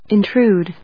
/ɪntrúːd(米国英語), ˌɪˈntru:d(英国英語)/